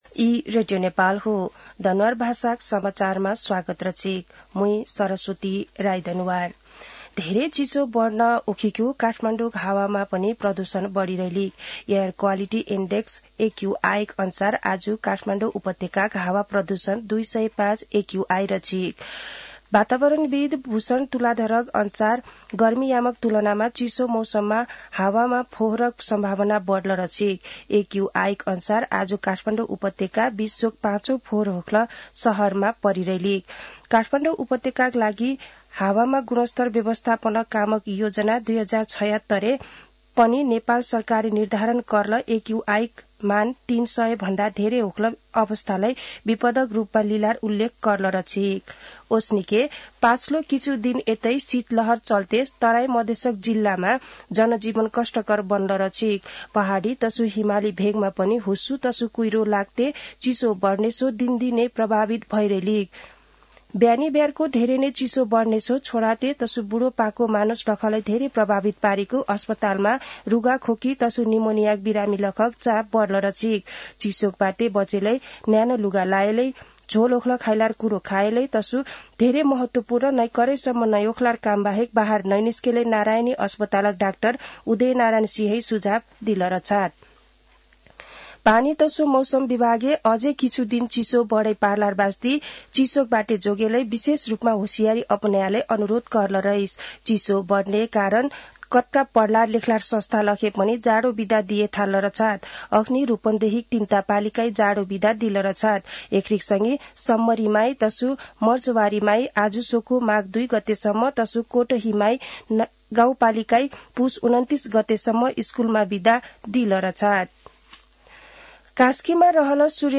दनुवार भाषामा समाचार : २३ पुष , २०८१
Danuwar-news-1.mp3